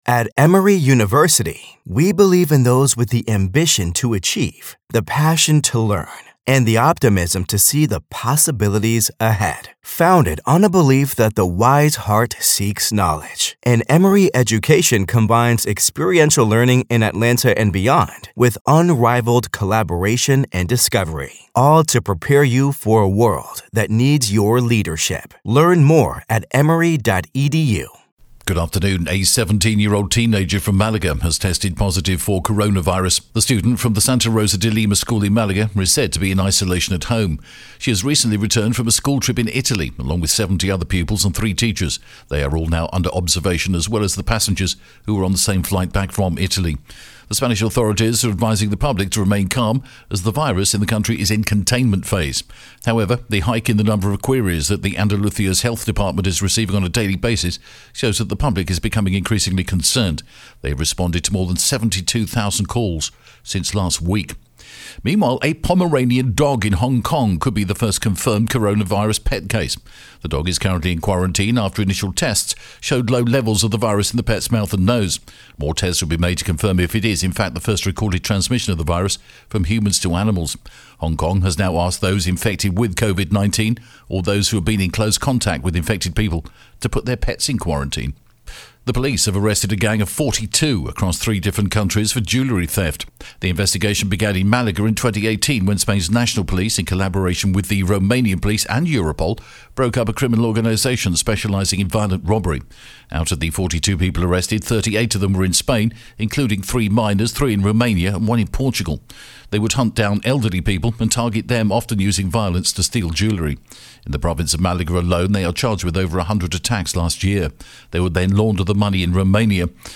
The latest Spanish news headlines in English: March 6th